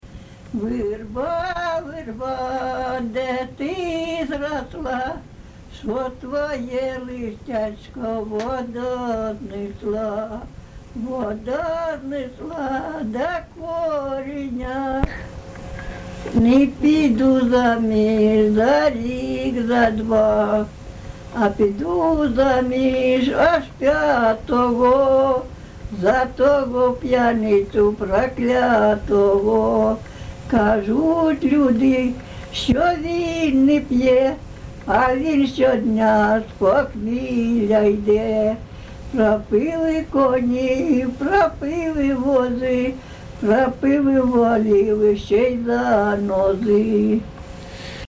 ЖанрПісні з особистого та родинного життя
Місце записус. Староварварівка, Краматорський район, Донецька обл., Україна, Слобожанщина